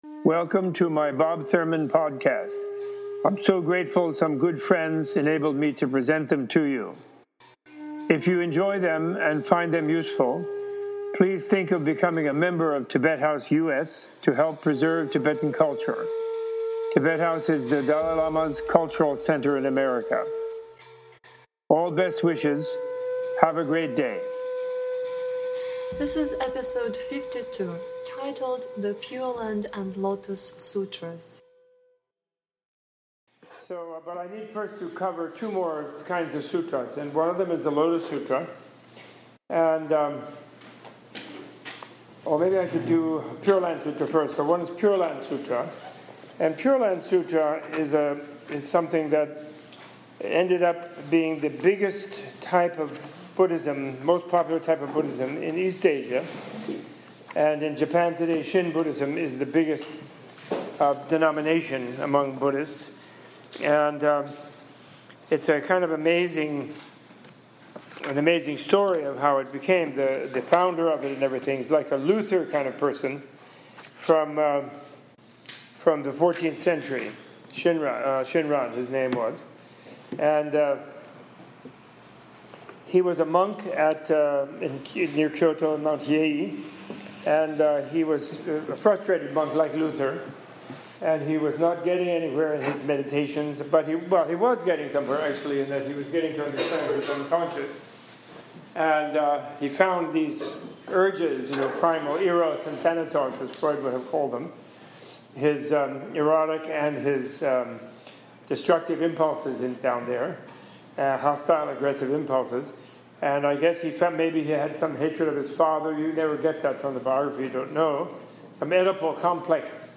This episode was recorded on October 15, 2015 in a Columbia class taught by Professor Thurman.